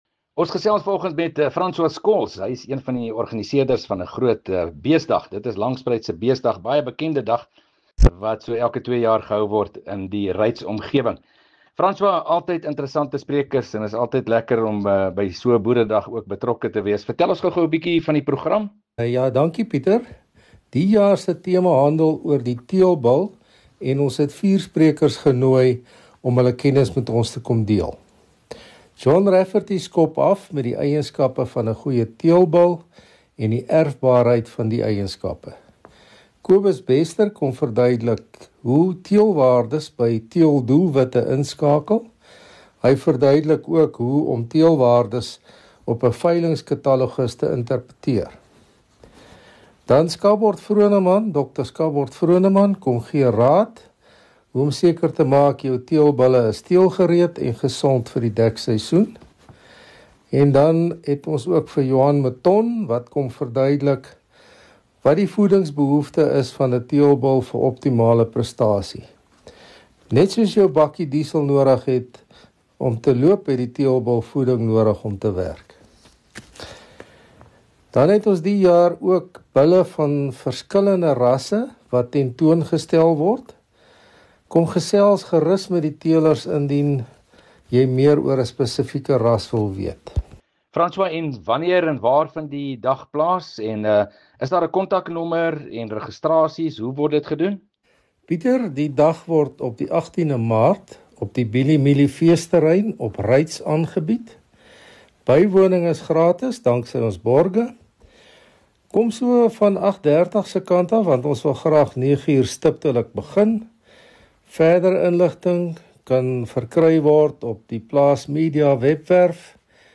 Landbou Insetsels 11 Mar Gewilde boeredag op Reitz 2 MIN Download